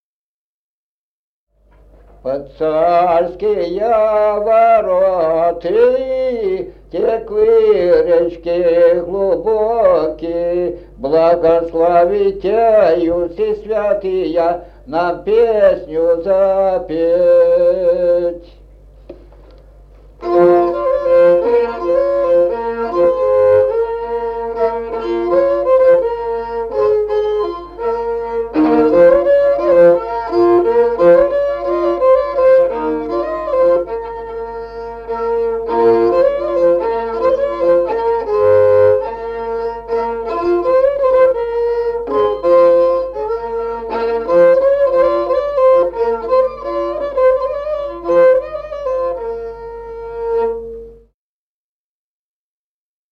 Музыкальный фольклор села Мишковка «Под царские вороты», свадебная, репертуар скрипача.